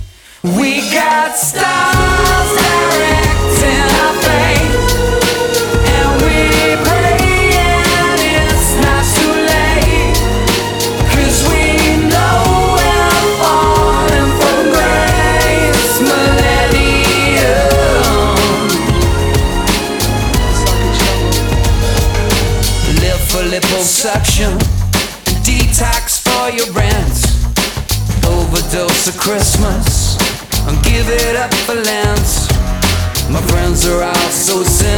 Pop Rock Adult Alternative Dance Britpop Pop Rock
Жанр: Поп музыка / Рок / Танцевальные / Альтернатива